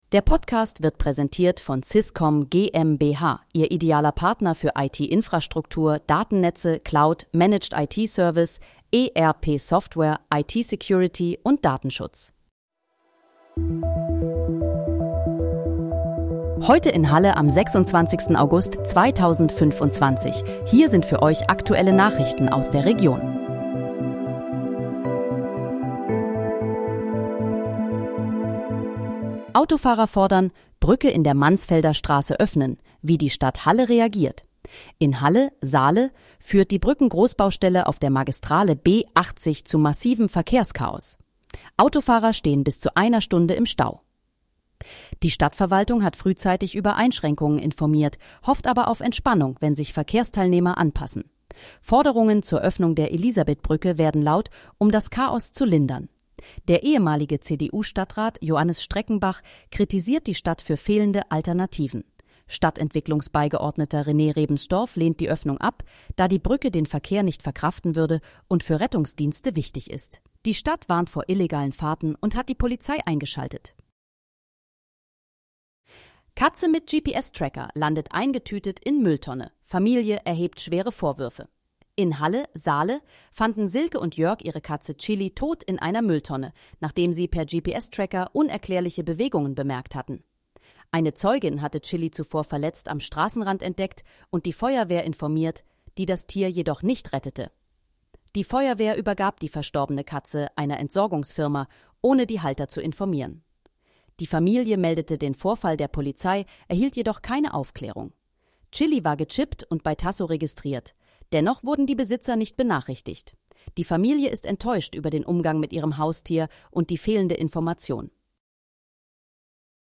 Heute in, Halle: Aktuelle Nachrichten vom 26.08.2025, erstellt mit KI-Unterstützung
Nachrichten